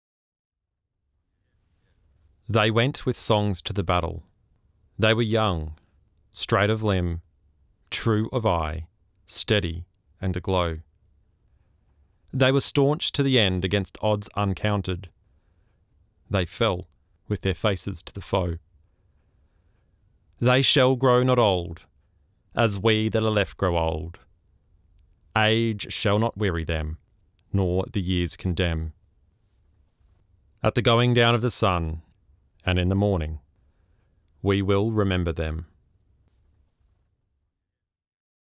Daniel Keighran VC reading the Ode
Play or download Victoria Cross recipient and Army veteran Daniel Keighran VC reciting the Ode.